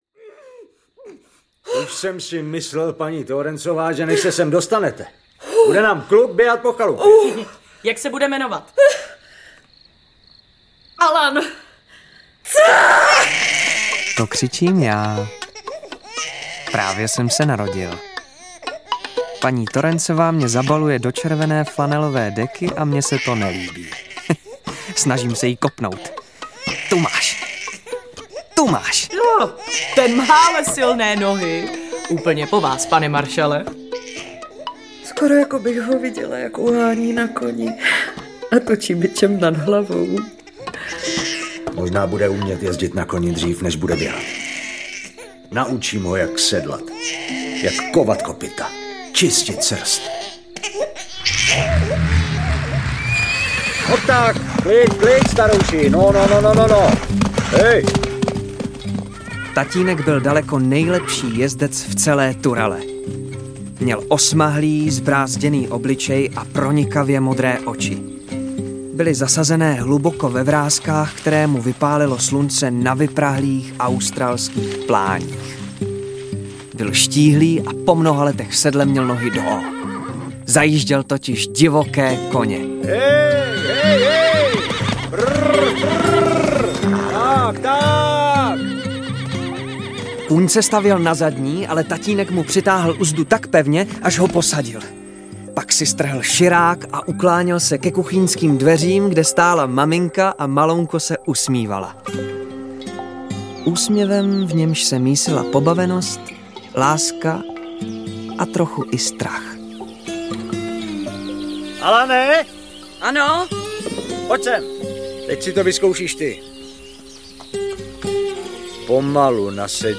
Audio kniha
Ukázka z knihy
Vyprávění se promenuje ve zpřítomněné vzpomínky a ozvuky důležitých prožitků, aby se znovu vrátilo k čistému vyprávění.